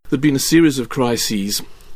crisis /ˈkraɪsɪs/ – crises /ˈkraɪsiːz/
The first change, the long // is very noticeable.